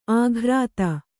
♪ āghrāta